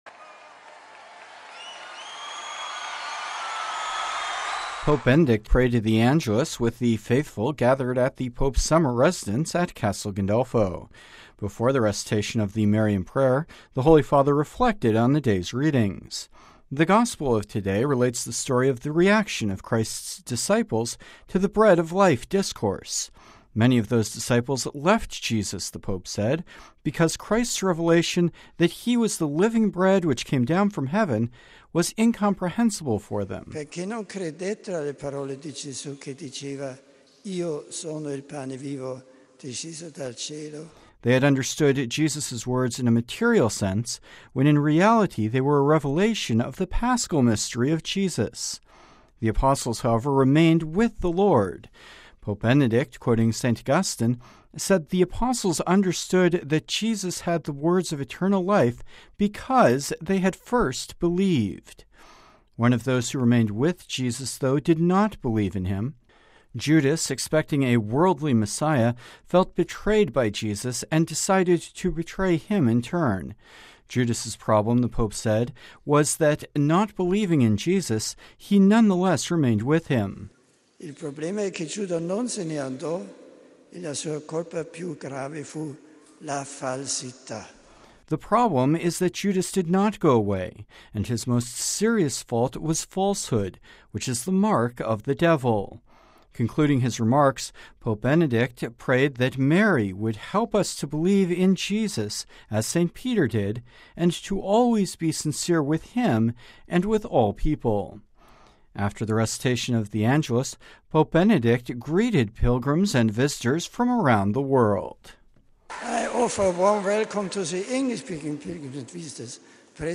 On Sunday, Pope Benedict prayed the Angelus with the faithful gathered at the Pope’s summer residence at Castel Gandalfo.